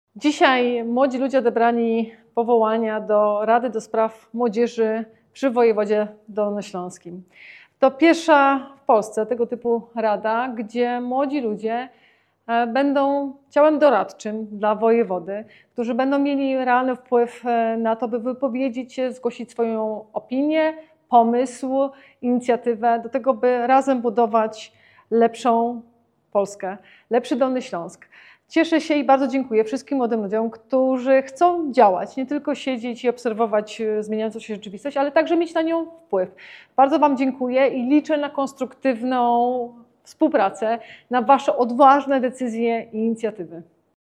Mówi Anna Żabska, Wojewoda Dolnośląska.